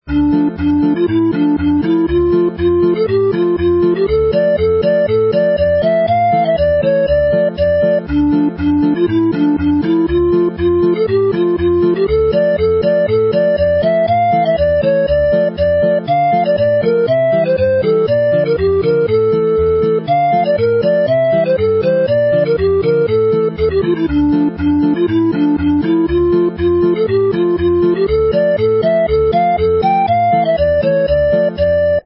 it is based on the key of D minor, with a cheering change to D major to close.
Morwyn y Pentre (the Village Maid) is suited superbly to the wooden flute